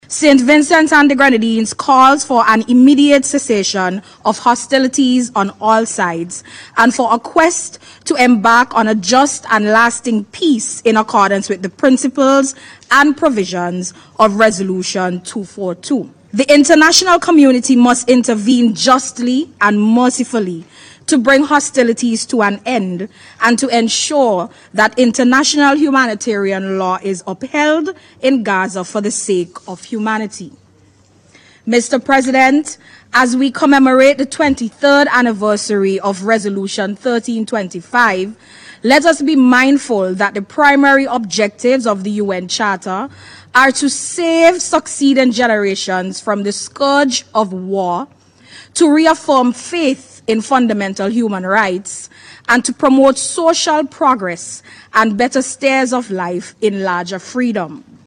KEISAL-AT-UNSC.mp3